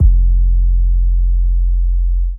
TM88 Gangster808.wav